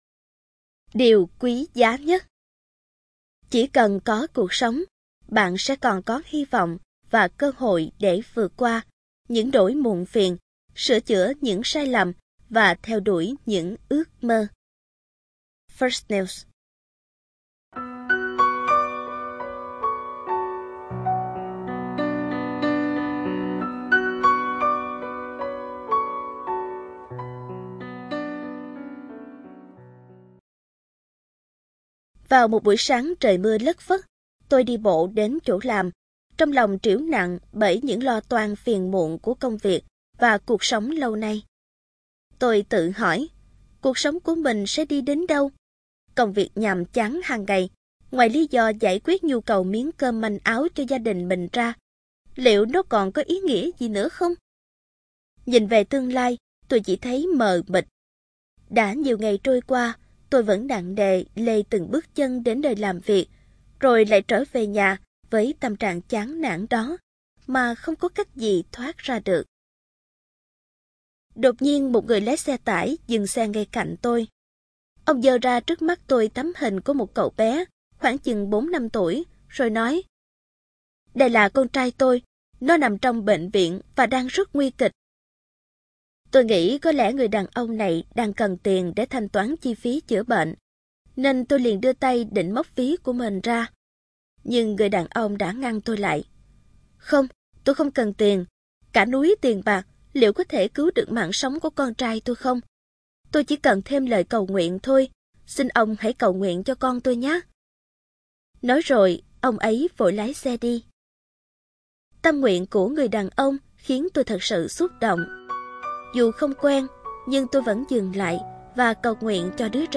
Người đọc